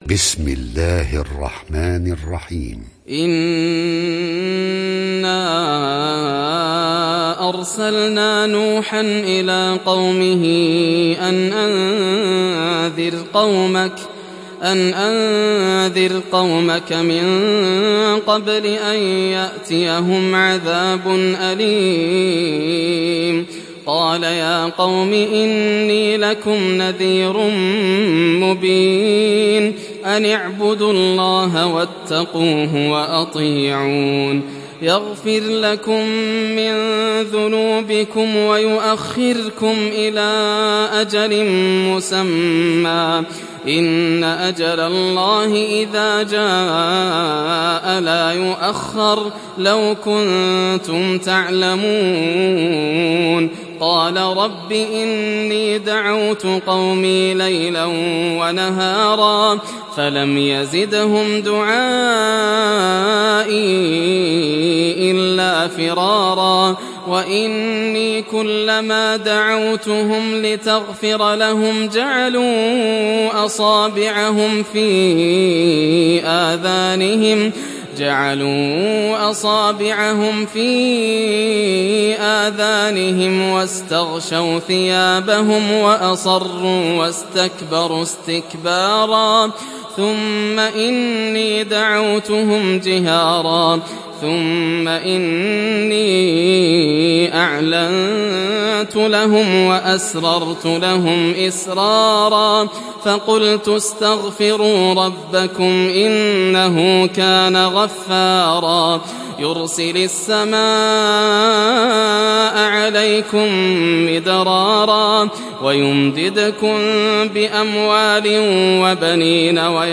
دانلود سوره نوح mp3 ياسر الدوسري روایت حفص از عاصم, قرآن را دانلود کنید و گوش کن mp3 ، لینک مستقیم کامل